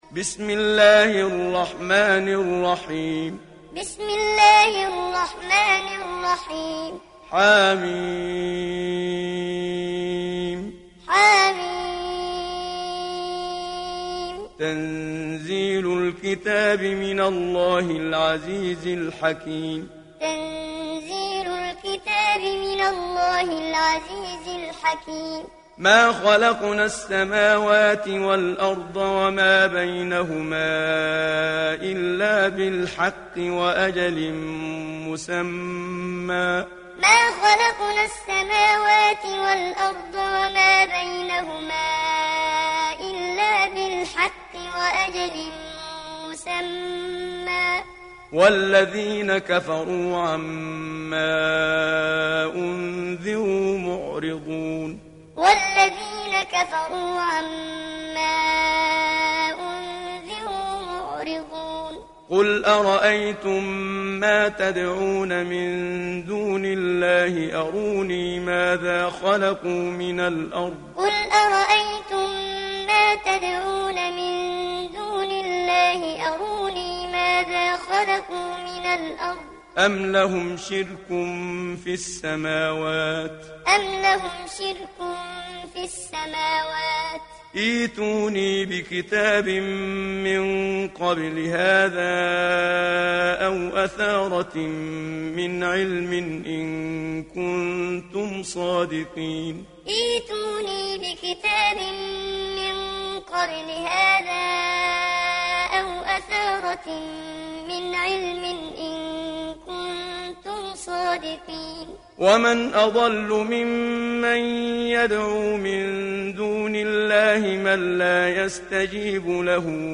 دانلود سوره الأحقاف محمد صديق المنشاوي معلم